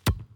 Soccer_Ball_Bounce_Series_01.wav